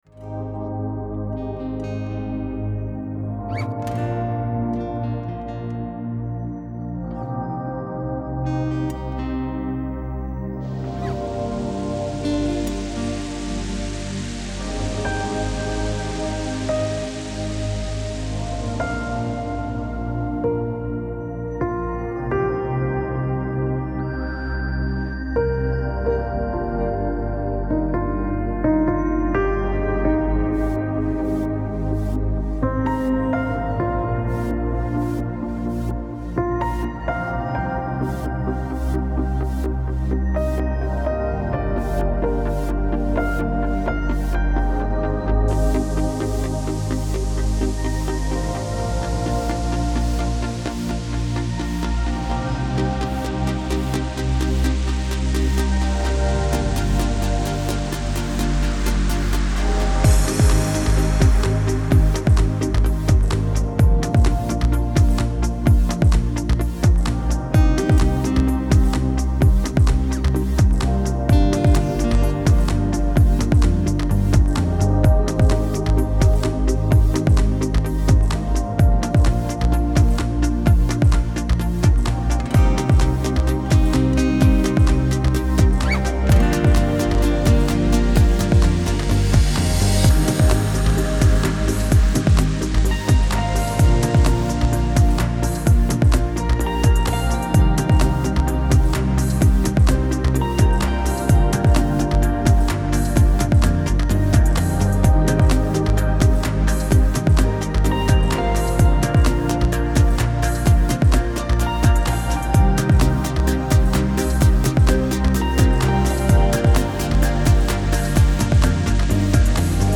موسیقی بی کلام الکترونیک ریتمیک آرام